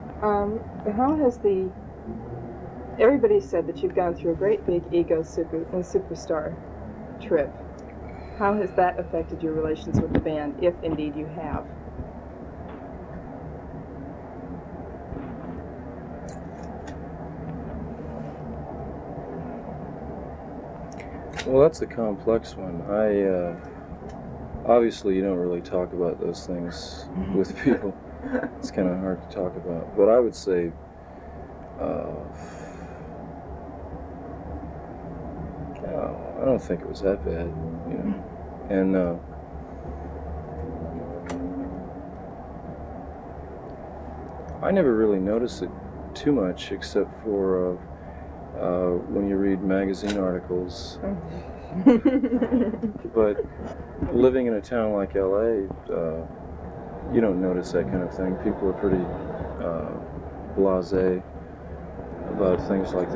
The Doors/The Lost Interview Tapes Featuring Jim Morrison - Volume Two The Circus Magazine Interview (Album)